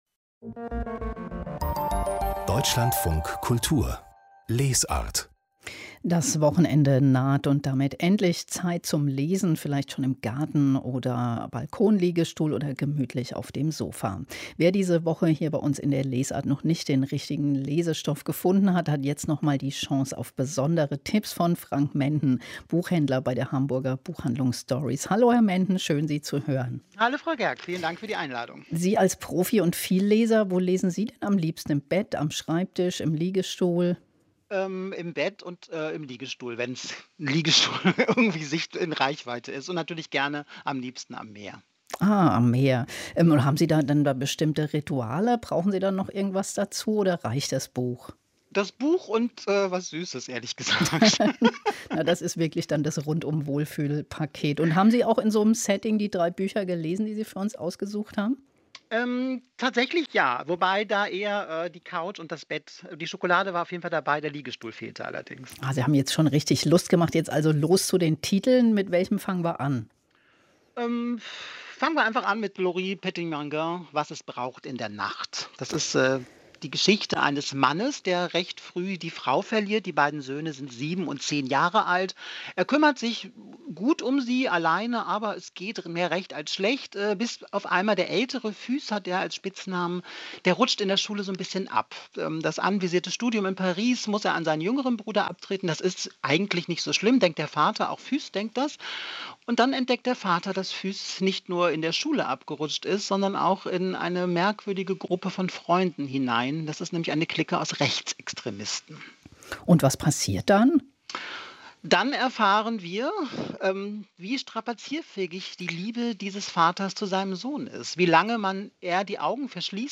Buchhändlergespräch: Stories! Die Buchhandlung